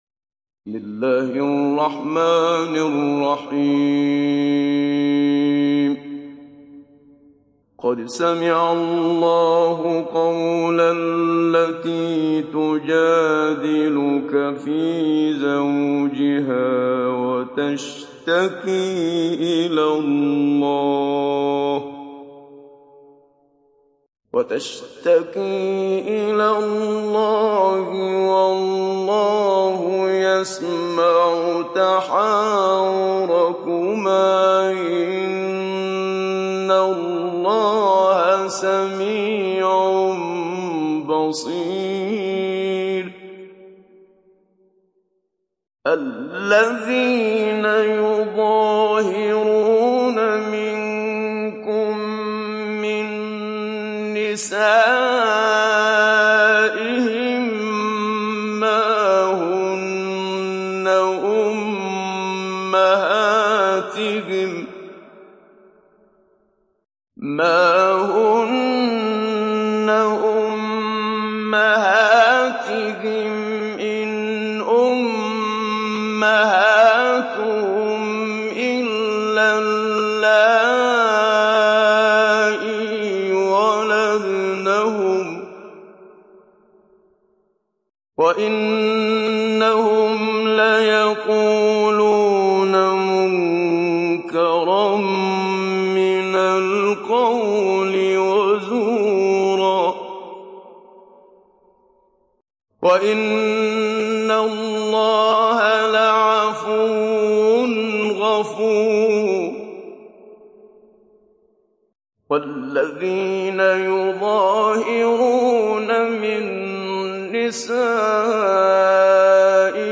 Download Surah Al Mujadilah Muhammad Siddiq Minshawi Mujawwad